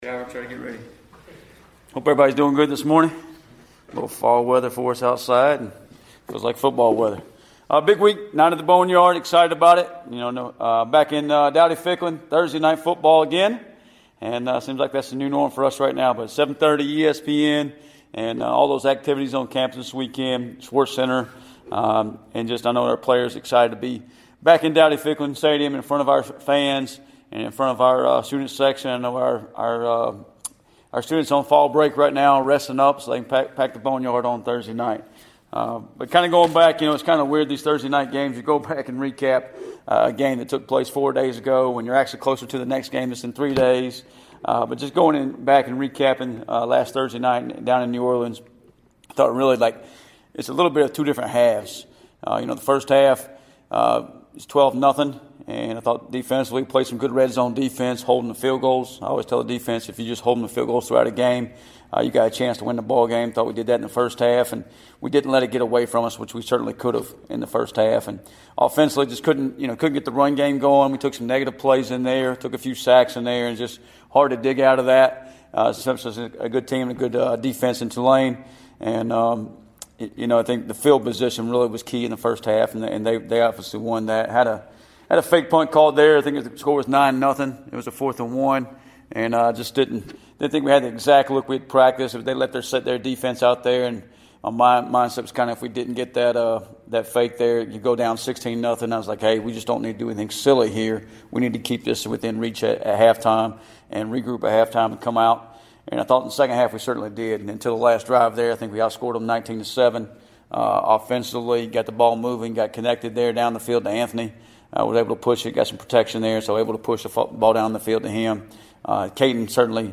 Weekly Press Conference
player interviews